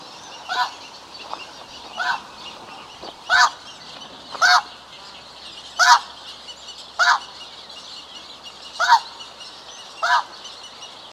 Oie des neiges - Mes zoazos
oie-des-neiges.mp3